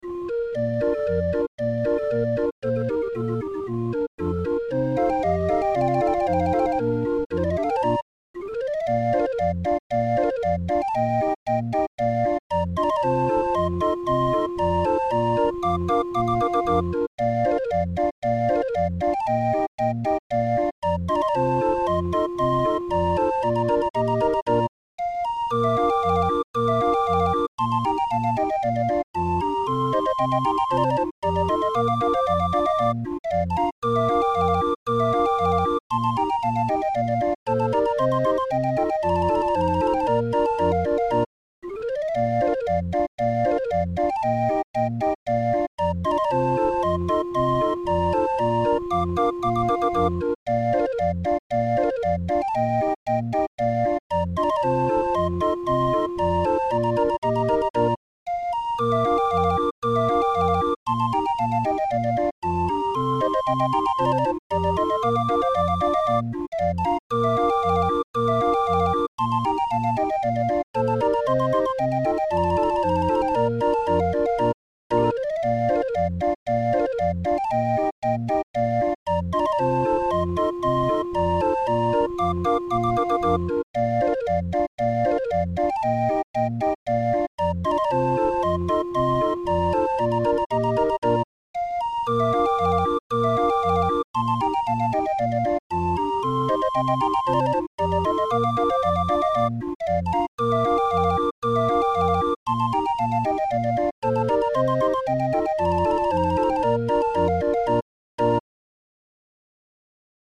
Muziekrol voor Raffin 20-er